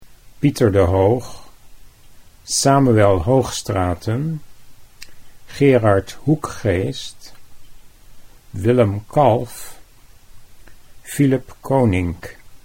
How to Pronounce the Names of Some Dutch Painting Masters